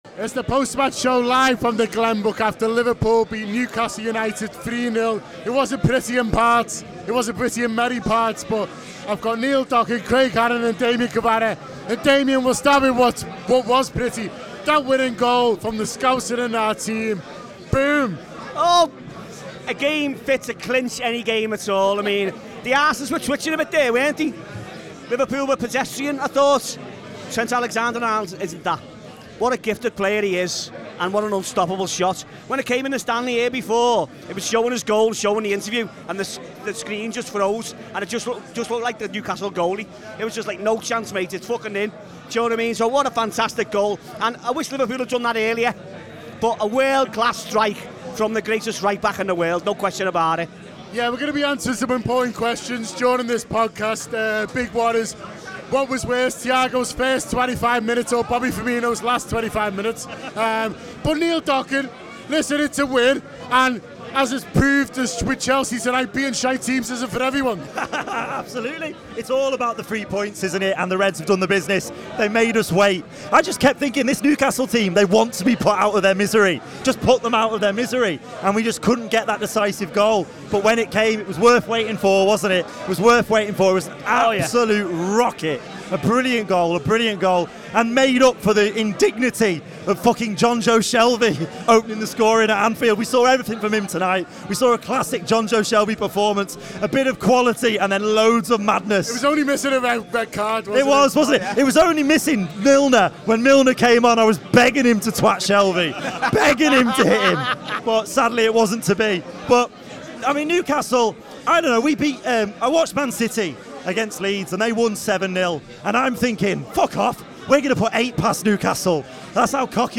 Post-match reaction podcast after Liverpool 3 Newcastle United 1 in the Premier League at Anfield, as The Reds cruise to victory.